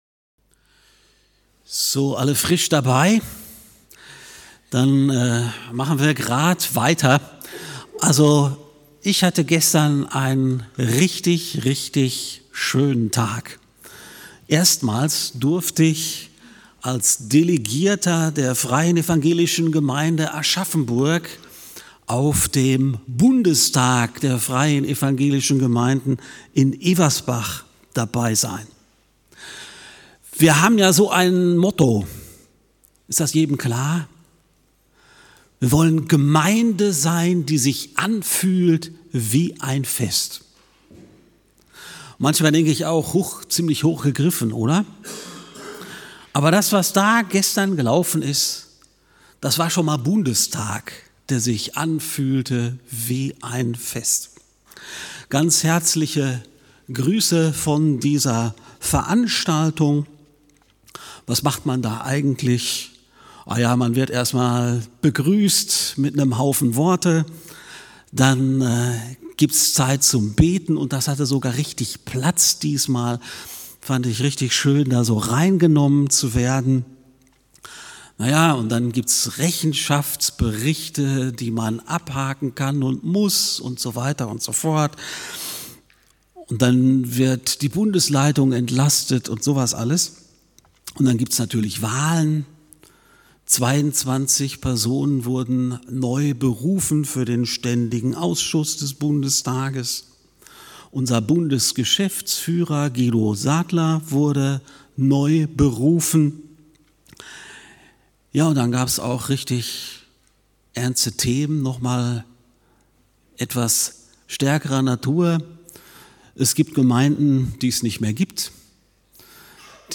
~ FeG Aschaffenburg - Predigt Podcast